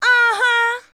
AHA  2.wav